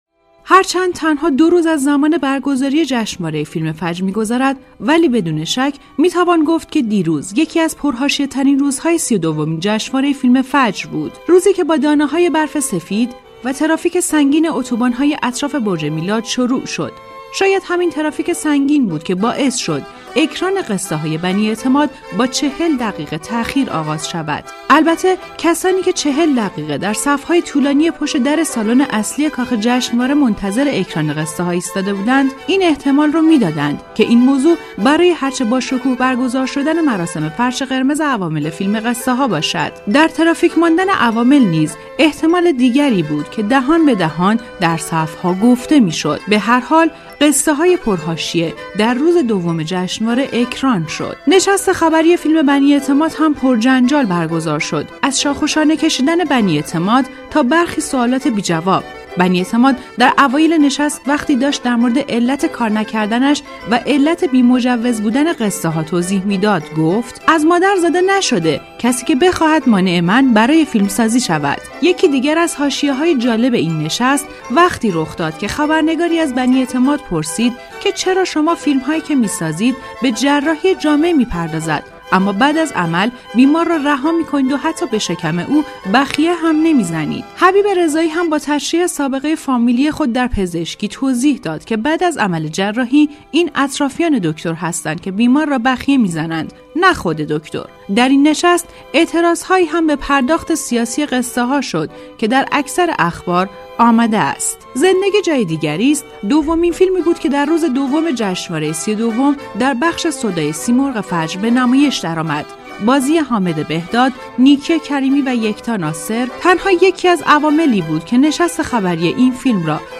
گزارش صوتی تسنیم از سی و دومین جشنواره بین‌المللی فیلم فجر ــ روز دوم